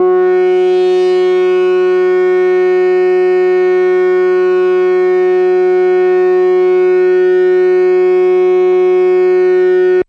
标签： midivelocity48 F4 midinote66 ArturiaMicrobrute synthesizer singlenote multisample
声道立体声